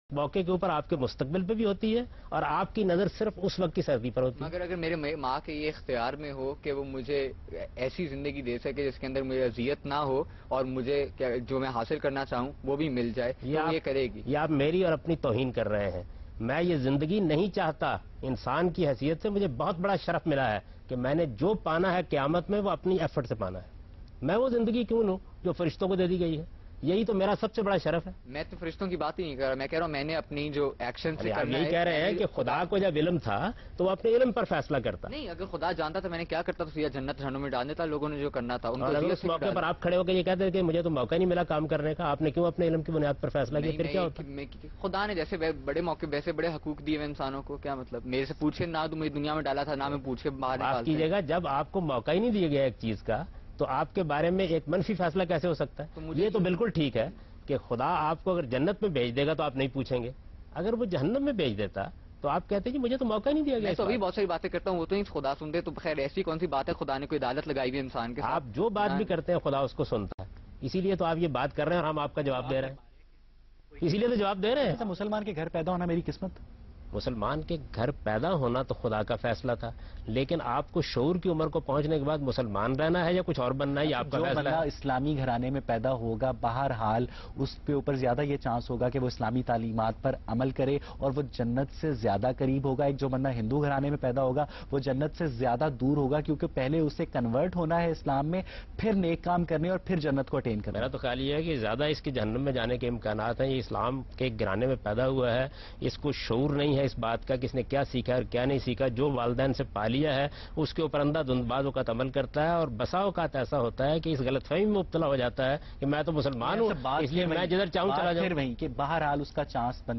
TV Programs
Questions and Answers on the topic “ The Determinism or Free will?” by today’s youth and satisfying answers by Javed Ahmad Ghamidi.